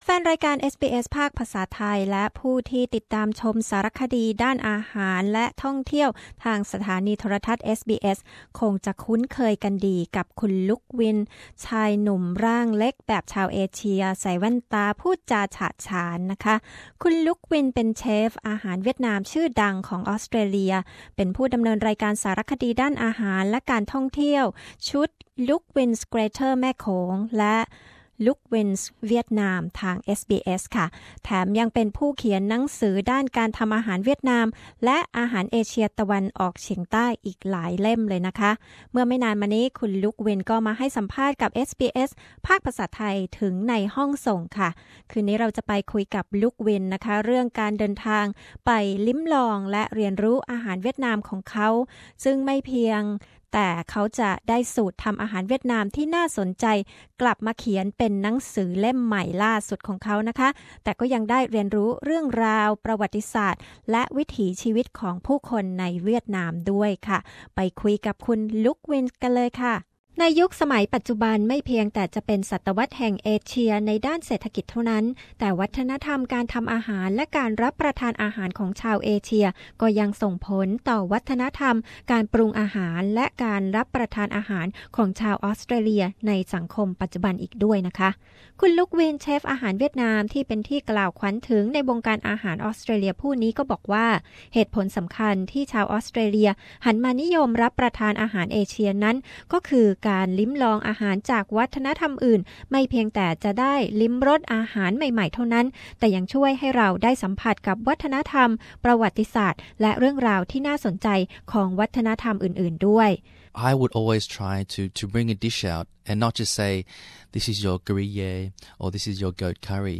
The second episode of an exclusive Interview with Luke Nguyen, the acclaimed Vietnamese restauranteur and host of SBSs popular South East Asian food travel show. He shares his views about impacts of Asian cuisine on modern Australian food and food role of in promoting multicultural values in Australia.